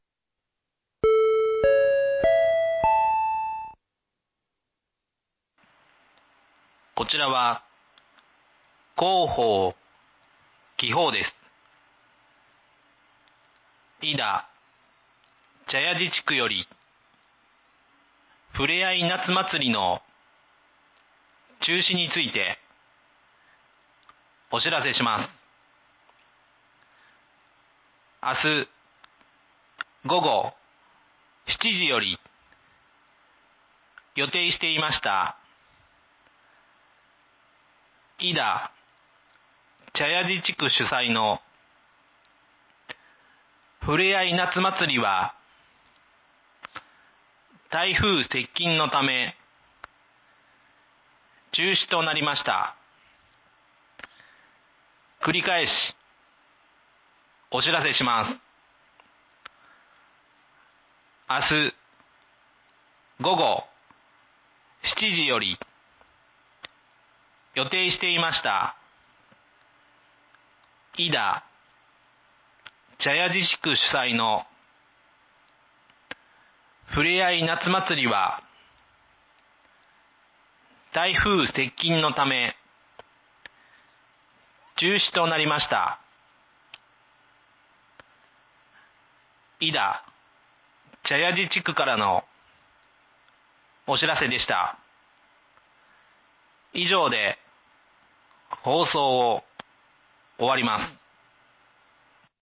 明日、午後７時より予定していました井田茶屋地地区主催の「ふれあい夏まつり」は、台風接近のため中止となりました。 （井田地区のみの放送です。）
放送音声